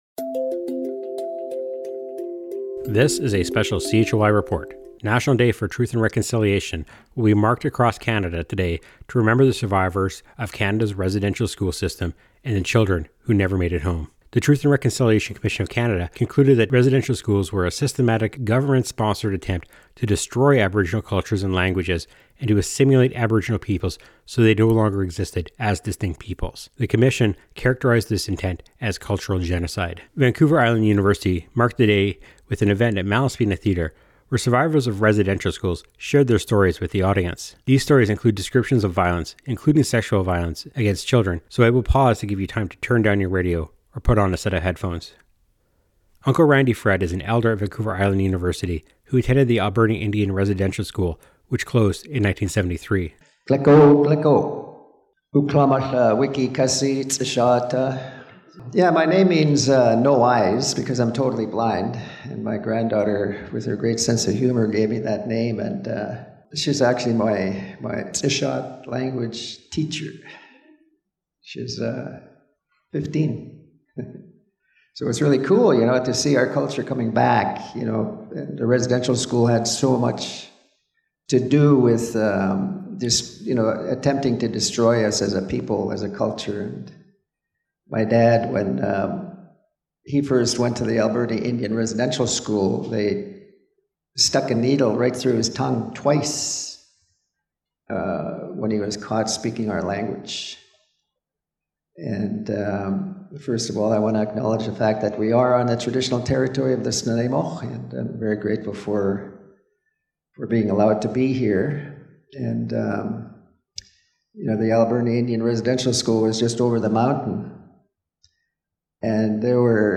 Residential school survivors tell their stories at VIU